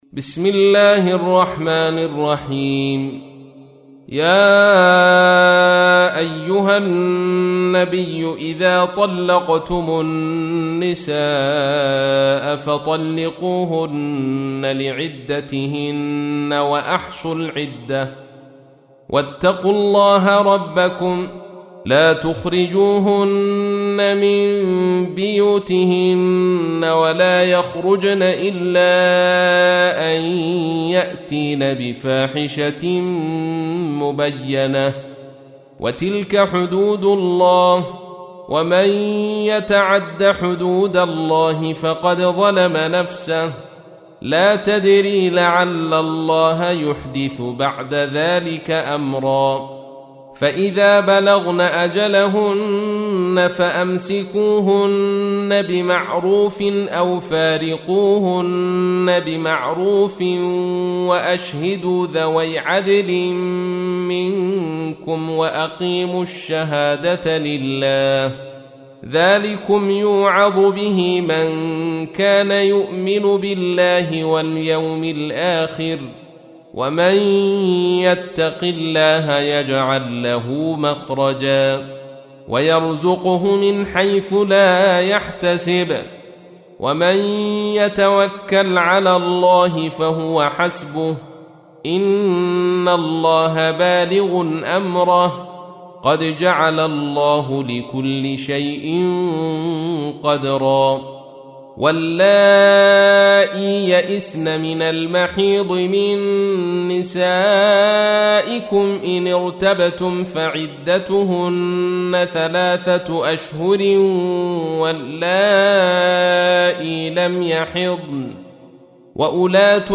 تحميل : 65. سورة الطلاق / القارئ عبد الرشيد صوفي / القرآن الكريم / موقع يا حسين